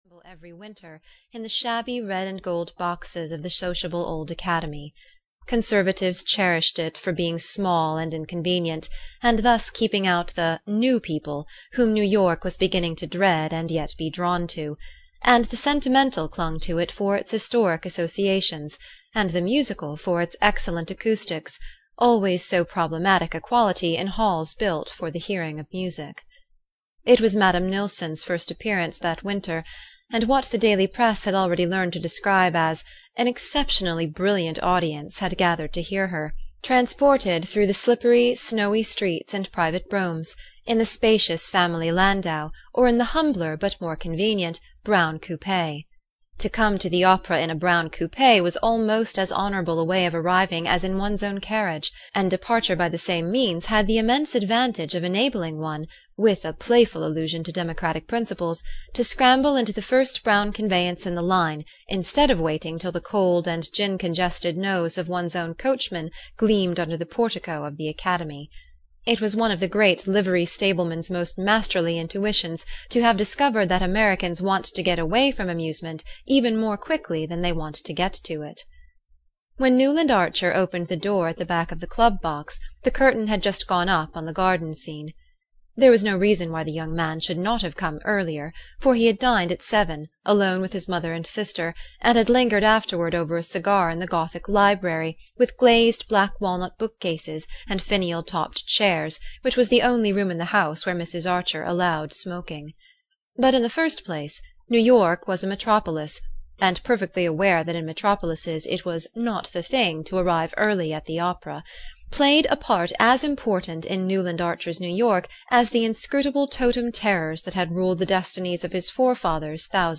The Age of Innocence (EN) audiokniha
Ukázka z knihy
the-age-of-innocence-en-audiokniha